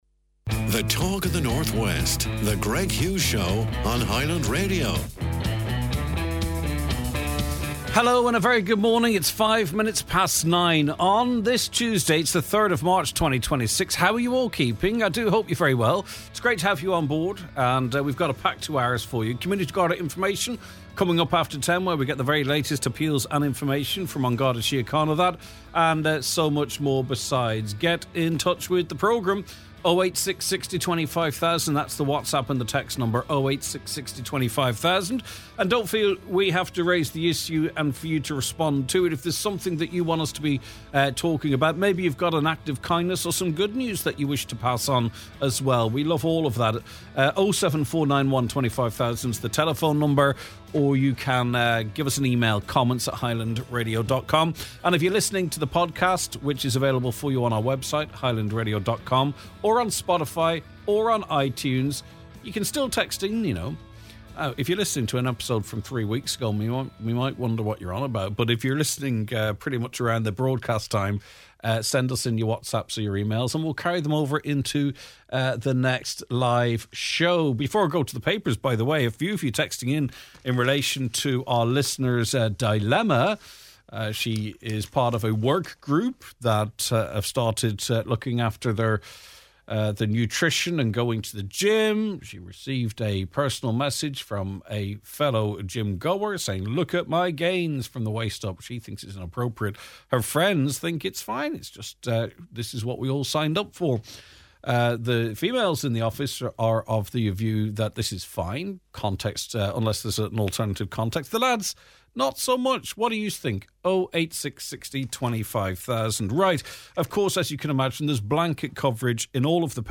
Buncrana Parking Row We hear from local politicians and members of the business community who are mobilizing against any moves to scrap the 30-minute free parking period in Buncrana.